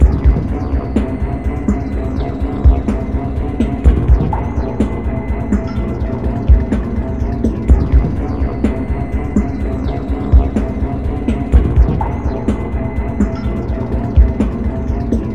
惊恐的旋律
Tag: 125 bpm Cinematic Loops Fx Loops 2.58 MB wav Key : Unknown